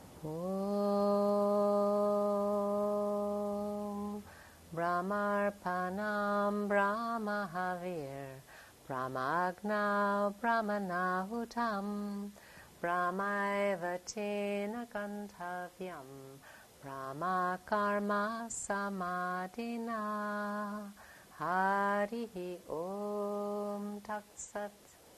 Video and Audio Yoga, Ayurveda and Self-Realization Workshop Food Prayer Audio Slides Workshop Slides